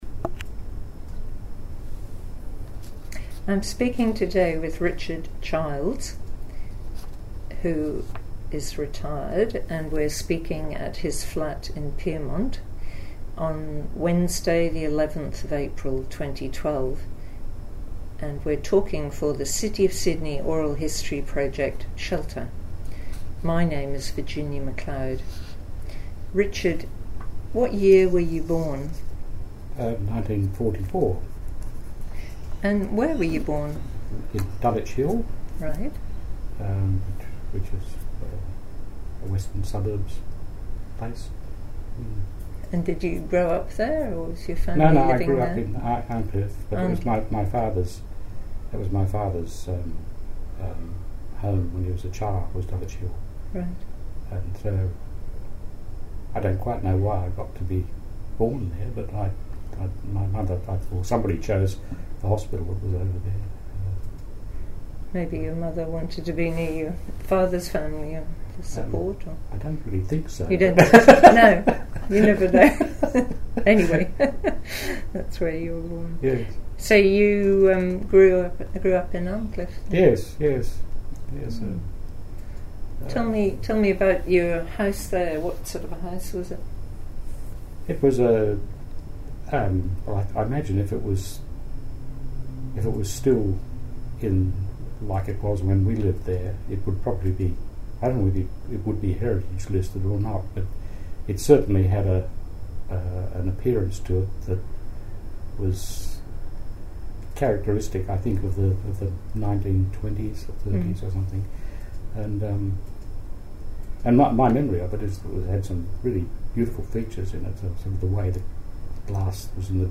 This interview is part of the City of Sydney's oral history theme: Shelter